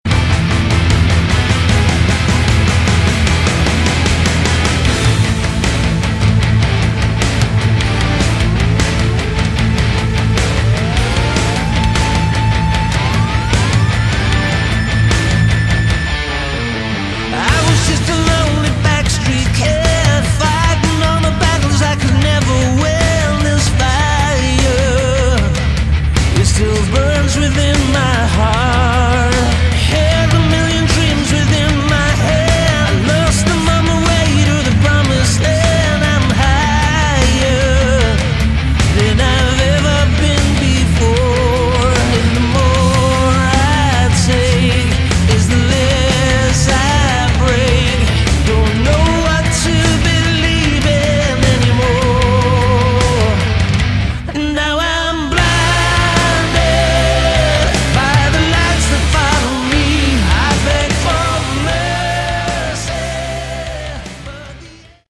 Category: Melodic Rock
vocals, guitars, keyboards
lead guitars
bass, backing vocals
drums, percussion
saxophone